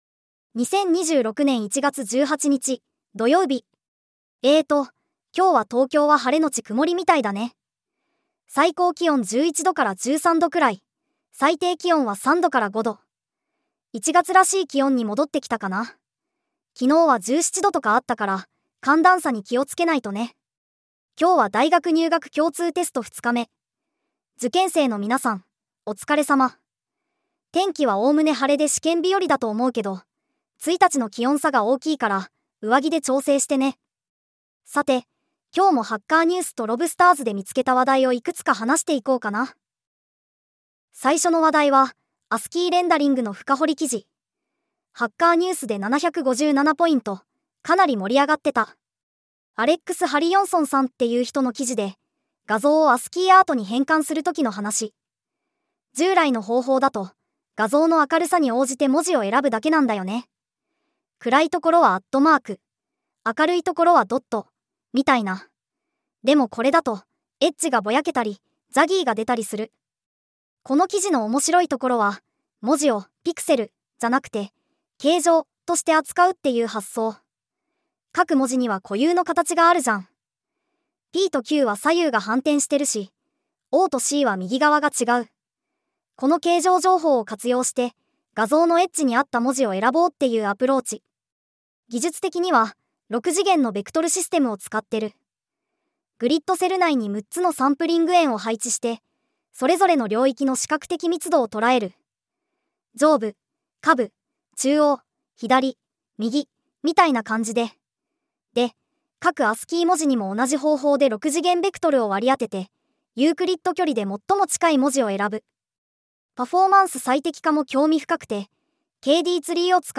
テトさんに技術系ポッドキャストを読んでもらうだけ